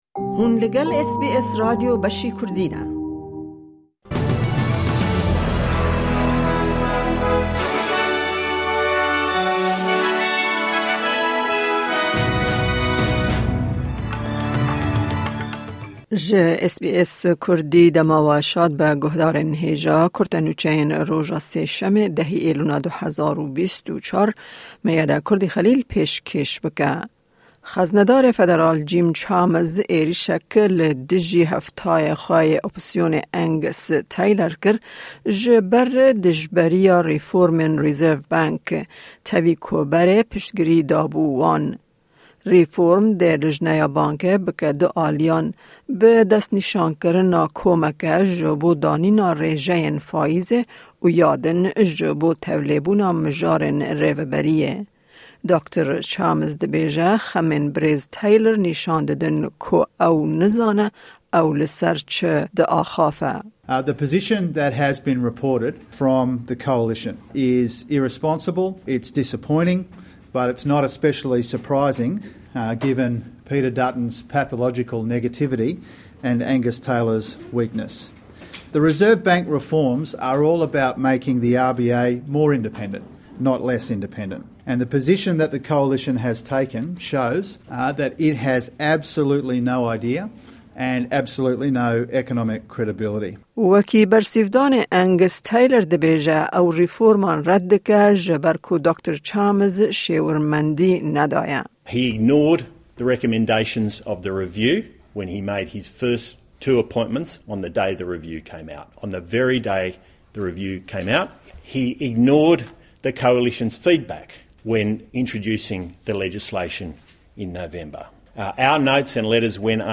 Kurte Nûçeyên roja Sêşemê 10î Îlona 2024